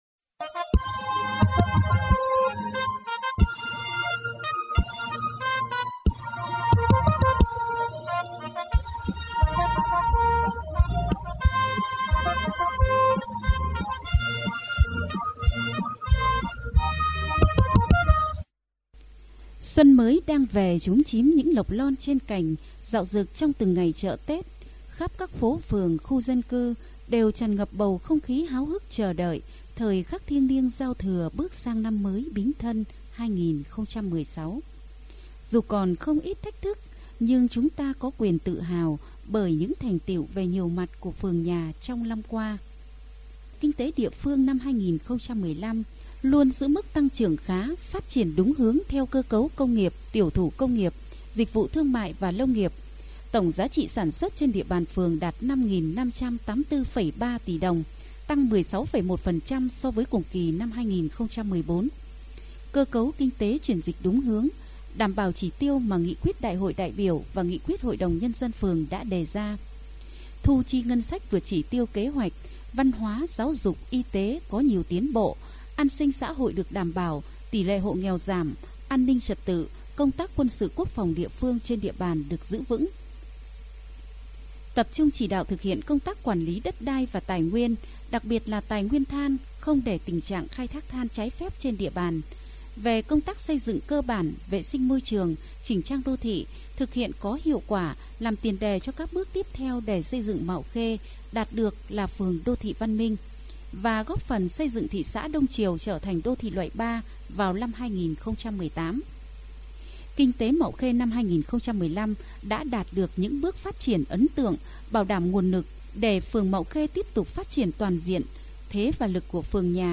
Chương trình được phát trên sóng FM truyền thanh phường Mạo Khê ngày tết Bính Thân 2016